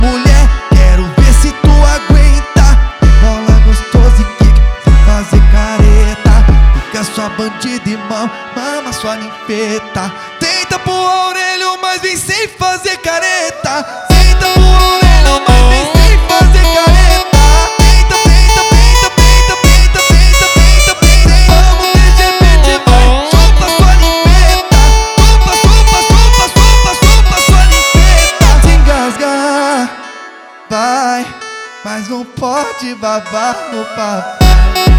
Жирный бас-клава и хлопковые гитары
Baile Funk Brazilian
Жанр: Фанк